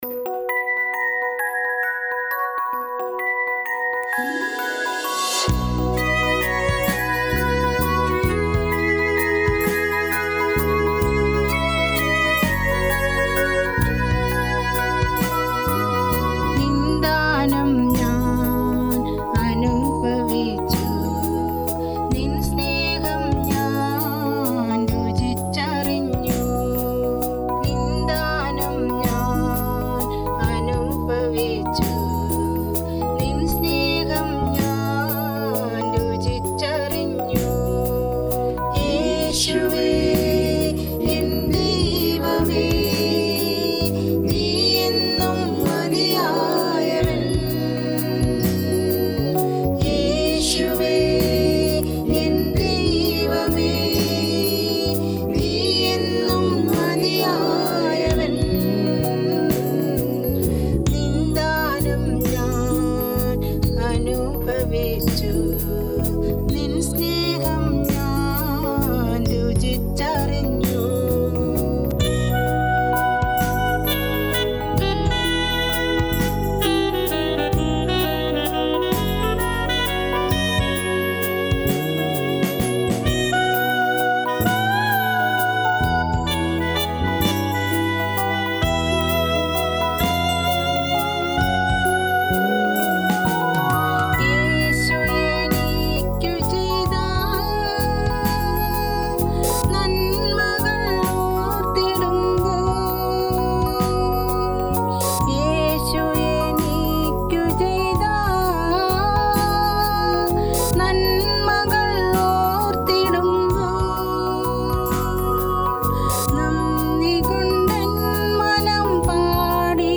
This is a Malayalam Christian song. Used Cubase 5.1. Used a normal mic. Vocals